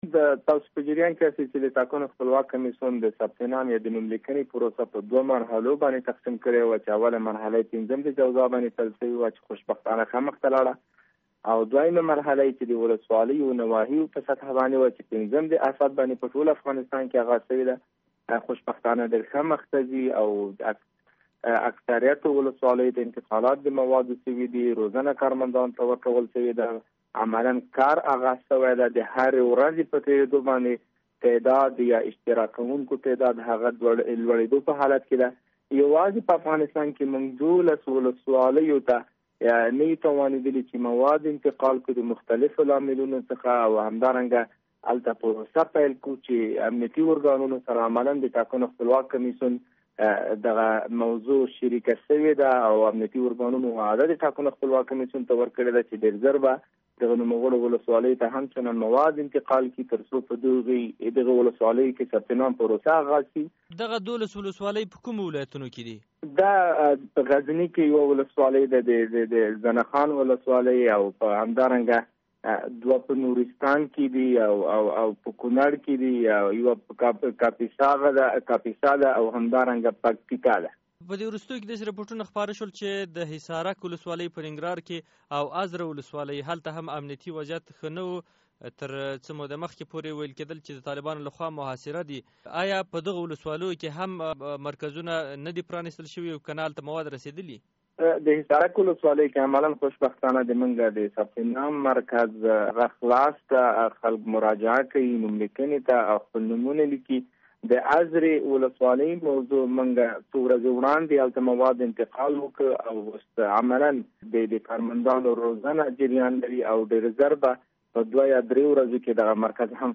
له ضیالحق امرخیل سره مرکه